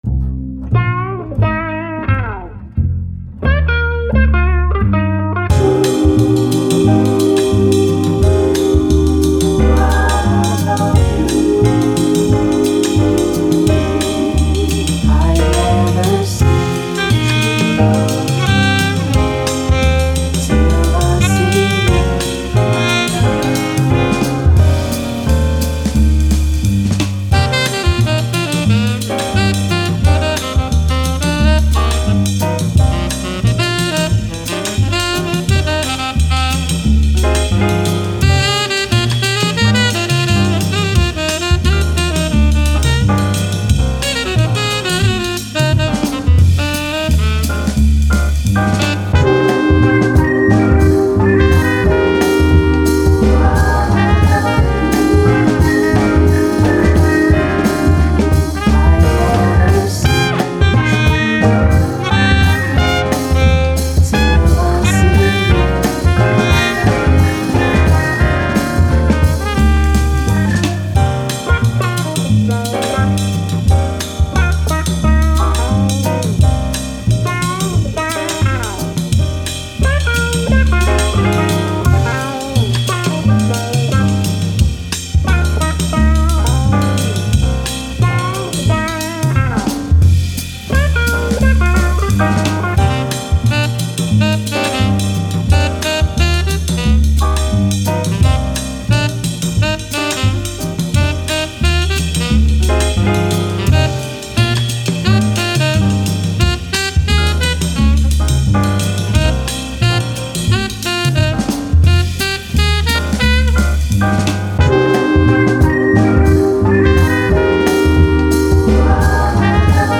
Jazz, Elegant, Chilled